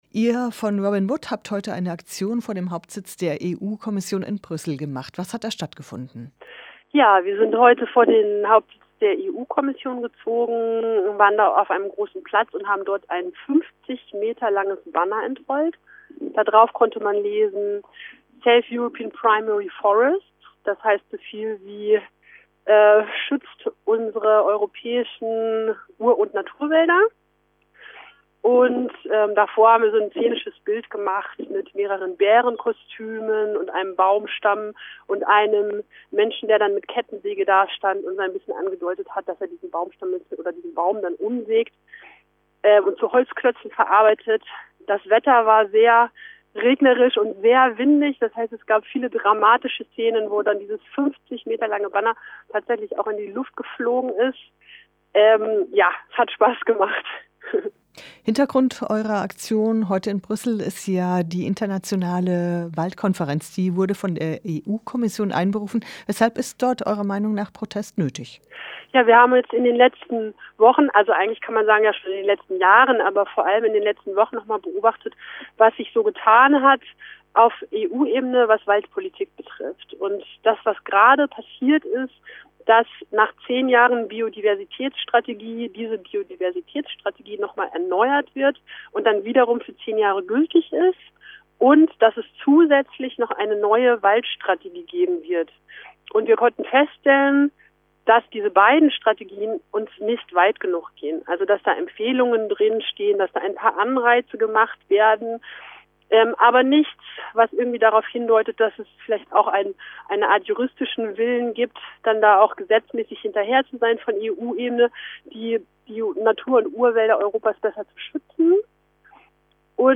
Podcast Interview